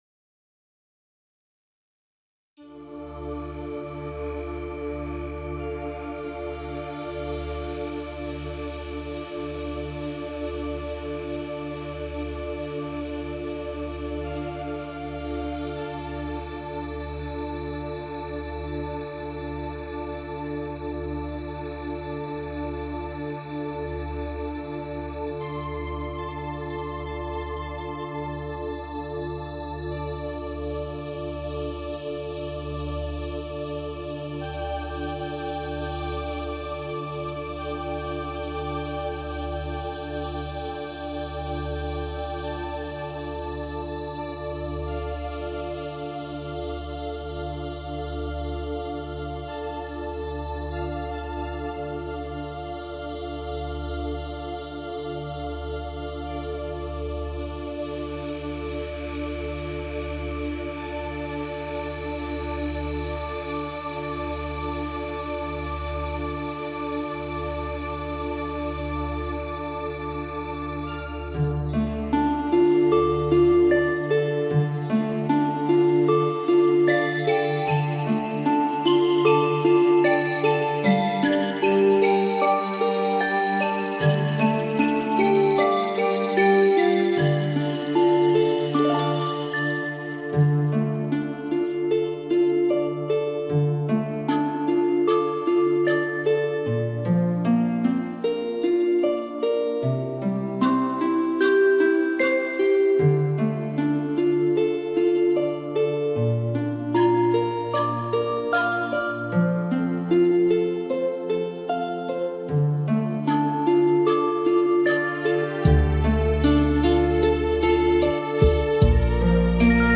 无 调式 : F 曲类